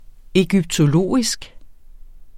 Udtale [ εgybtoˈloˀisg ]